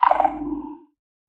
Minecraft Version Minecraft Version 25w18a Latest Release | Latest Snapshot 25w18a / assets / minecraft / sounds / mob / warden / tendril_clicks_1.ogg Compare With Compare With Latest Release | Latest Snapshot
tendril_clicks_1.ogg